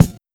Track 02 - Kick OS 01.wav